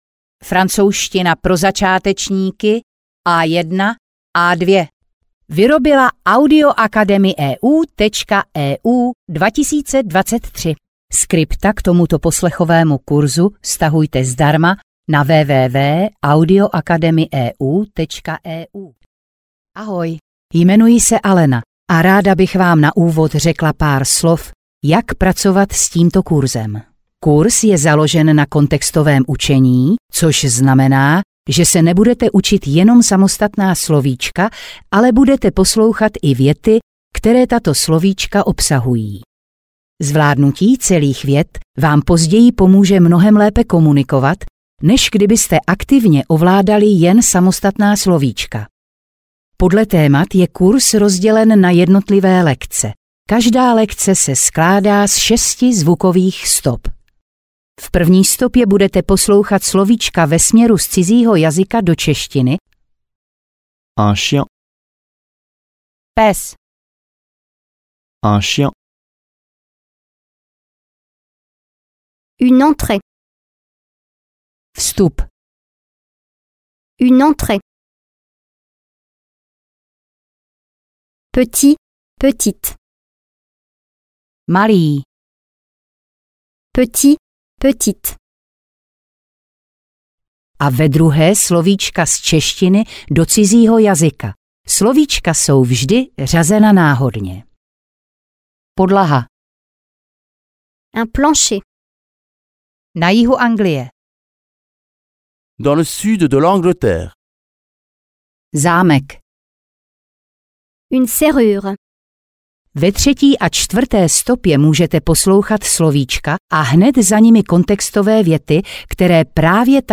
Francouzština pro začátečníky A1-A2 audiokniha
Ukázka z knihy